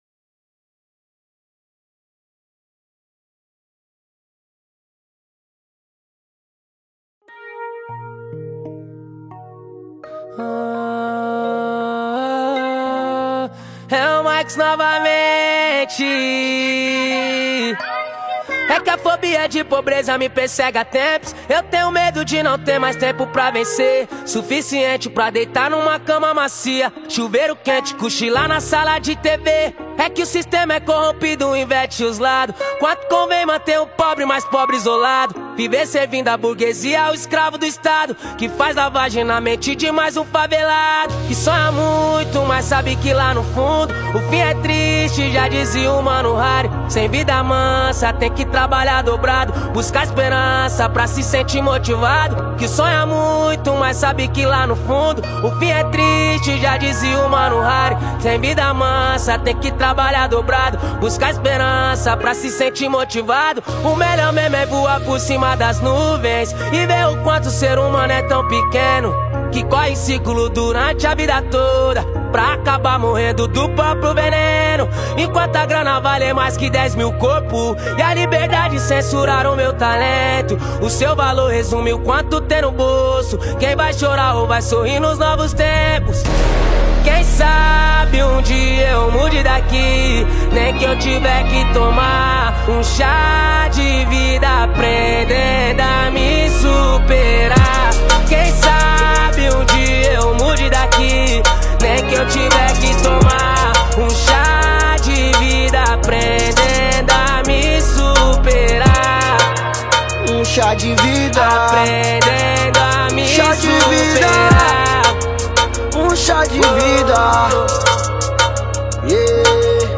2024-07-25 00:49:58 Gênero: Funk Views